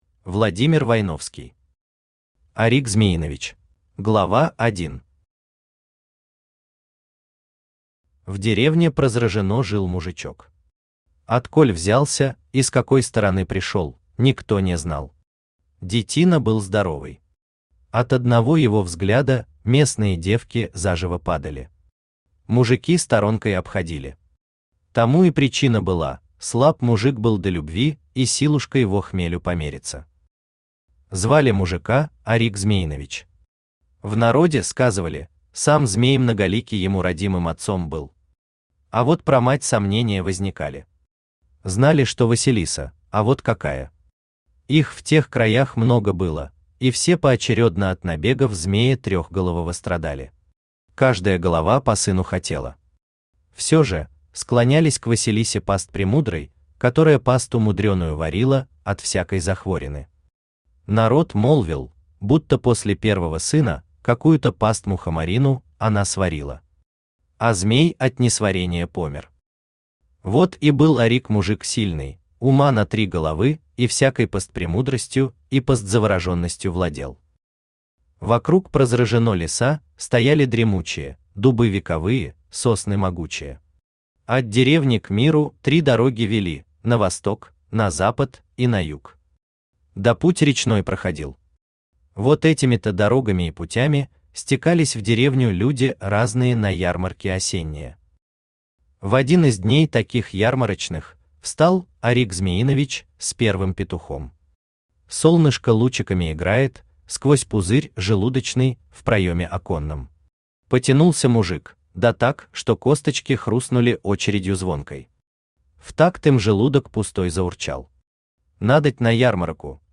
Аудиокнига Орик Змеинович | Библиотека аудиокниг
Aудиокнига Орик Змеинович Автор Владимир Войновский Читает аудиокнигу Авточтец ЛитРес.